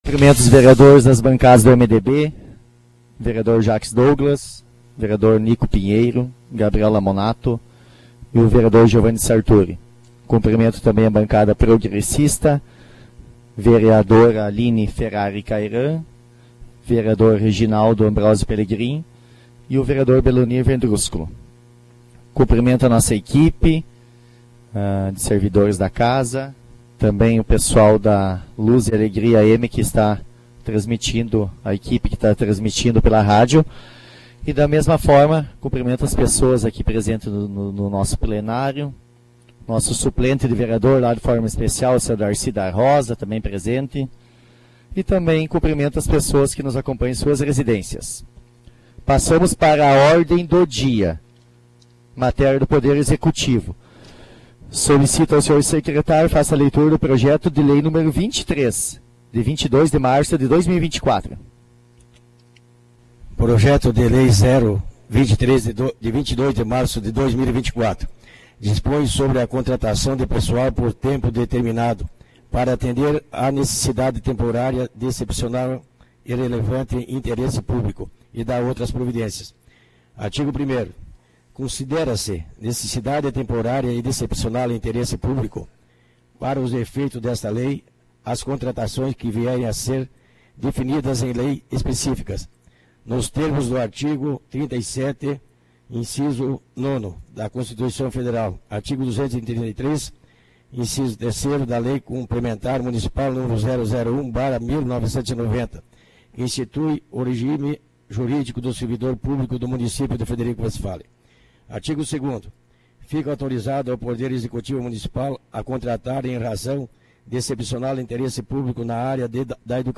Sessão Extraordinária do dia 26 de março de 2024